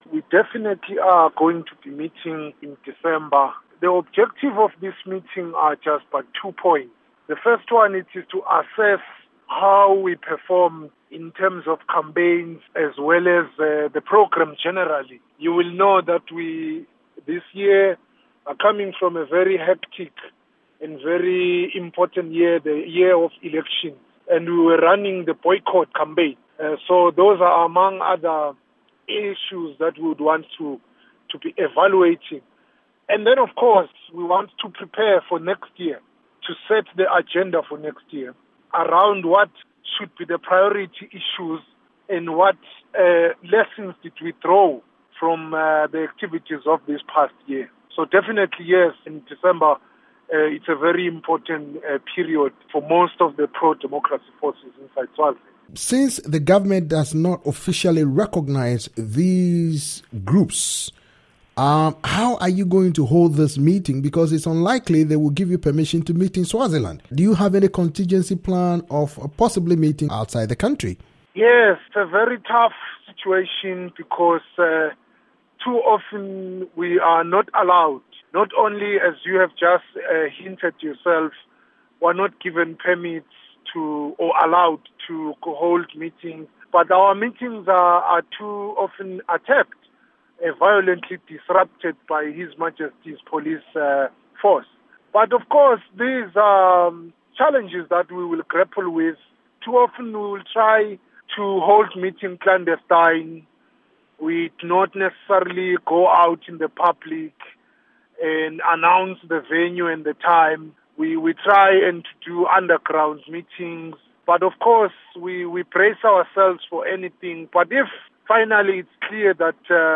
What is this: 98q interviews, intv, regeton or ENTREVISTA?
intv